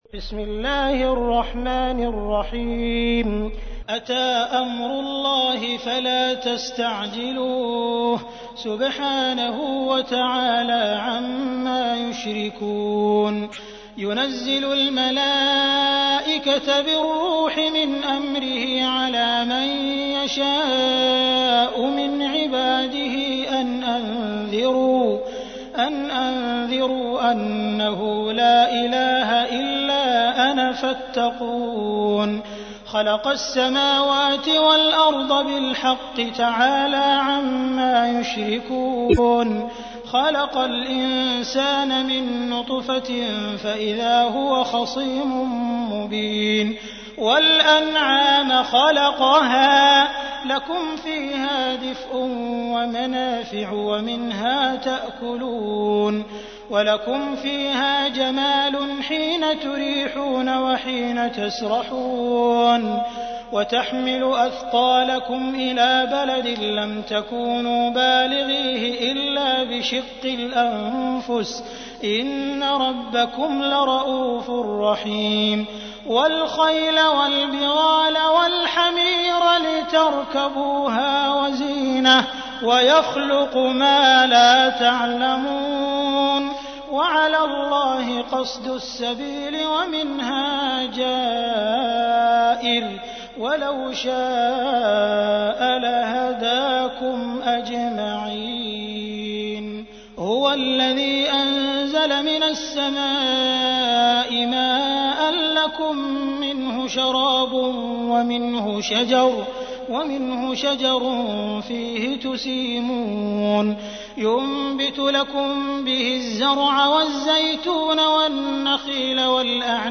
تحميل : 16. سورة النحل / القارئ عبد الرحمن السديس / القرآن الكريم / موقع يا حسين